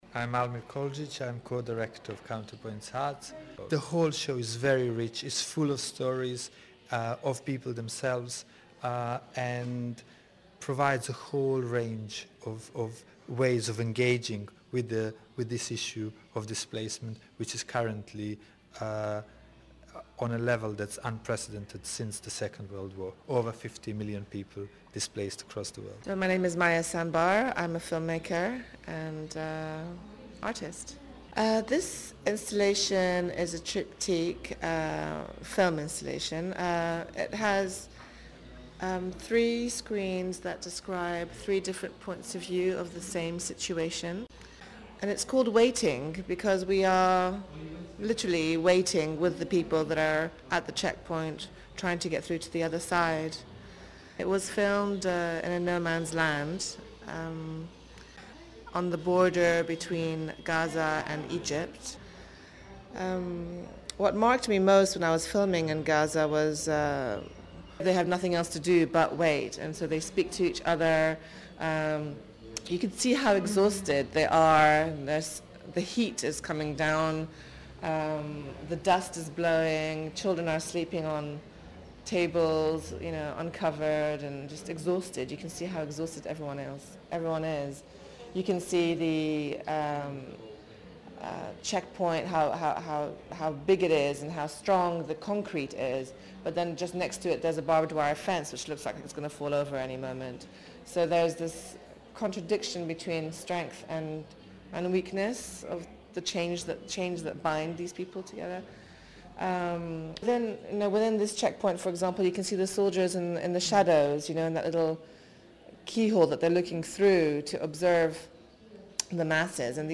went along to visit the exhibitions and spoke to some of those involved